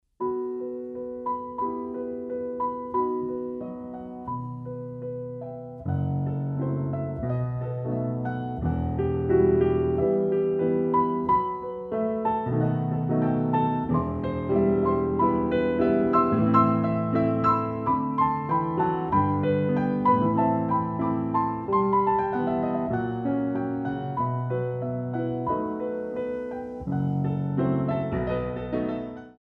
Compositions for Ballet Class